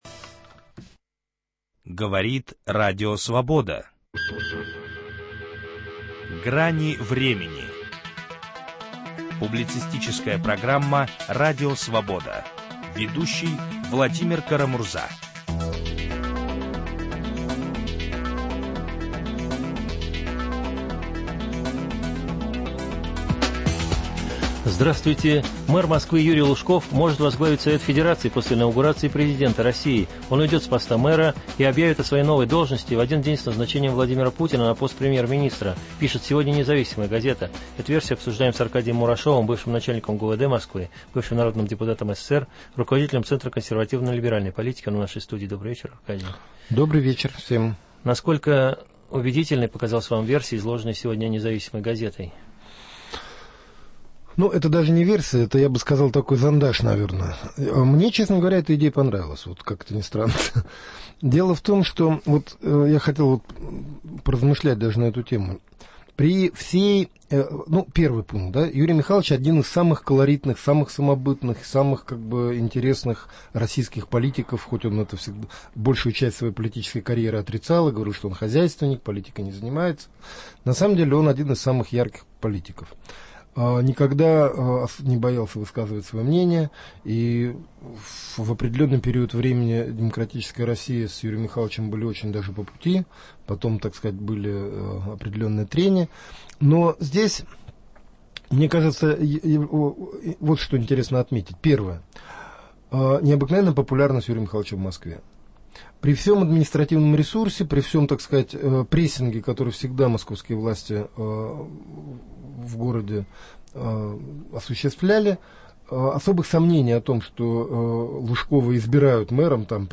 Есть разные точки зрения, многие из них - в "Гранях времени". Ведущий Владимир Кара-Мурза предлагает соглашаться, уточнять, дополнять и спорить, сделать программу дискуссионным клубом.